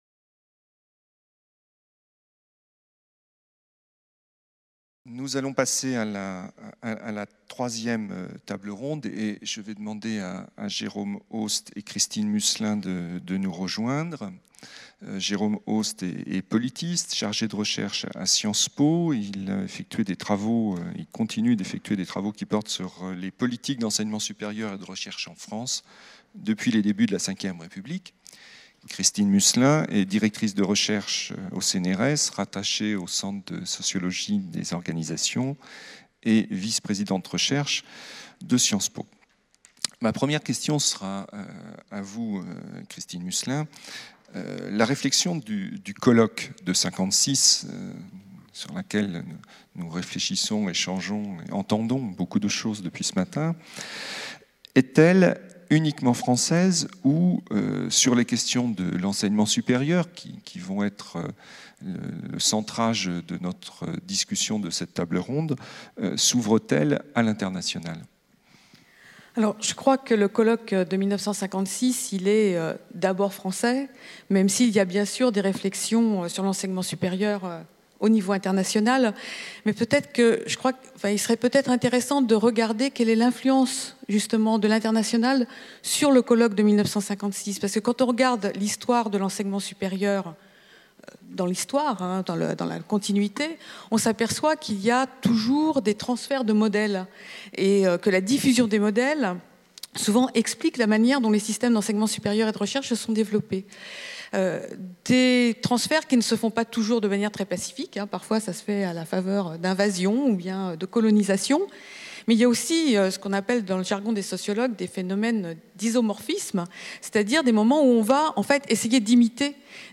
C’est le sens de la journée nationale qui s'est tenue à Caen le 3 novembre 2016 - journée qui s'est conclue par une allocution du président de la République.